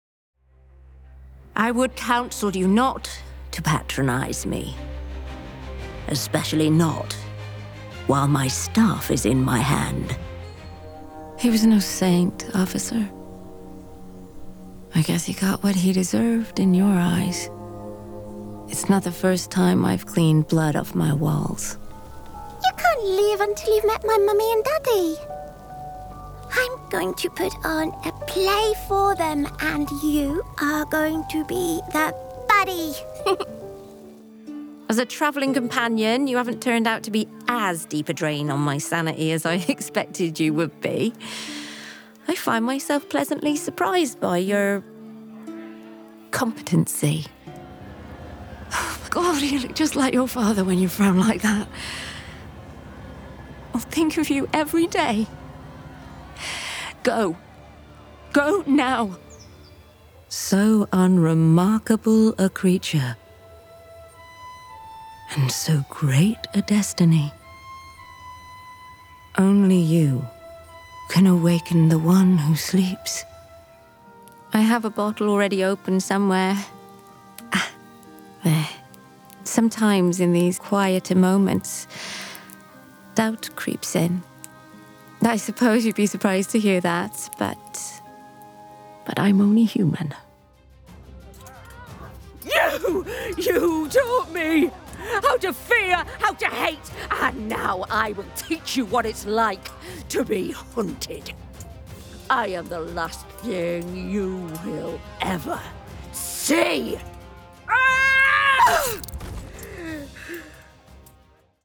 Video Game Showreel
Female
Neutral British
British RP
Bright
Confident
Friendly
Reassuring
Warm
Youthful